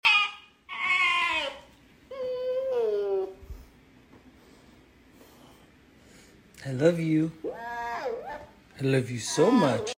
Just an arctic fox and sound effects free download
Just an arctic fox and his happy sounds